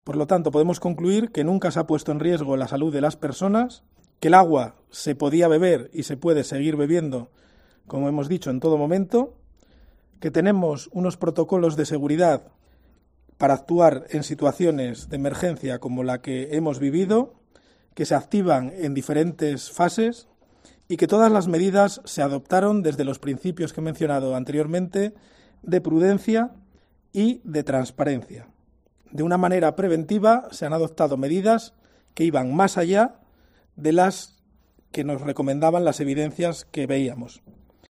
Declaraciones de Pelayo García, edil de Servicios Urbanos